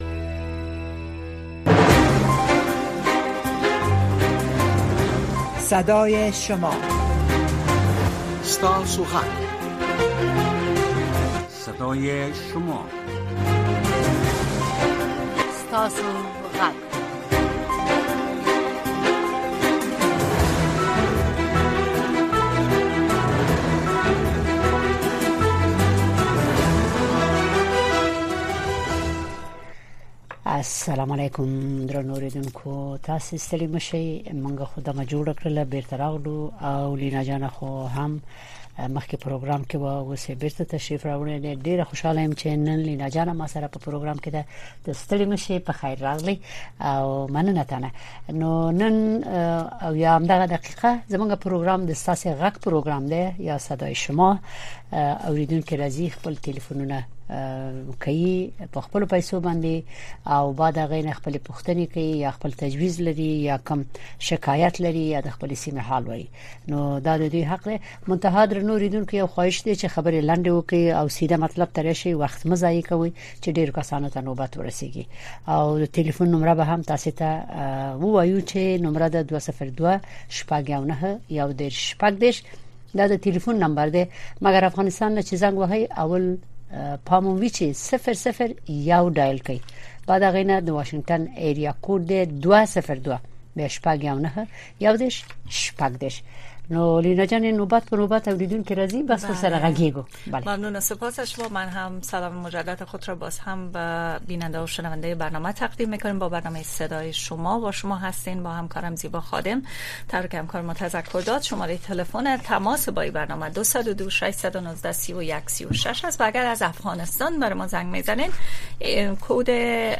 در برنامۀ صدای شما شنوندگان رادیو آشنا صدای امریکا به گونۀ مستقیم با ما به تماس شده و نظریات، نگرانی‌ها، دیدگاه، انتقادات و شکایات شان را با گردانندگان و شنوندگان این برنامه در میان می‌گذارند. این برنامه به گونۀ زنده از ساعت ۱۰:۰۰ تا ۱۰:۳۰ شب به وقت افغانستان نشر می‌شود.